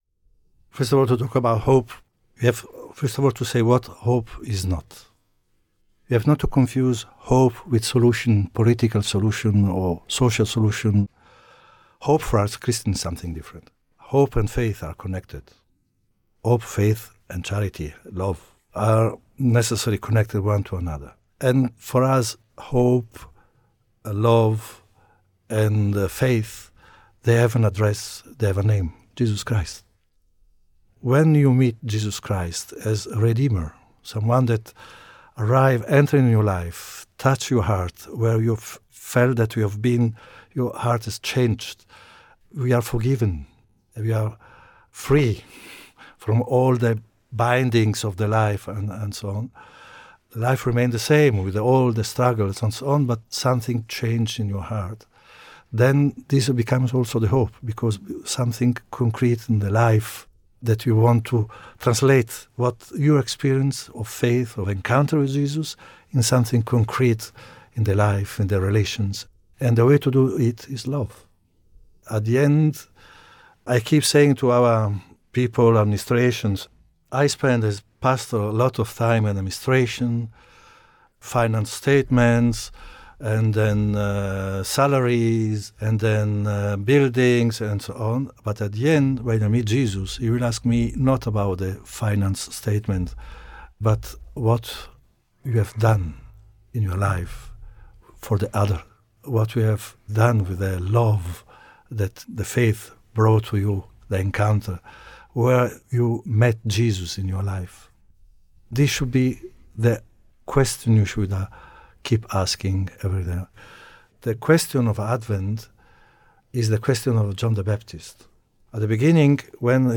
The Latin Patriarch of Jerusalem, His Beatitude Pierbattista Pizzaballa, took some time out of his very busy schedule to be guest of honour in a joint Catholic News / Middle East Analysis podcast just before the First Sunday of Advent.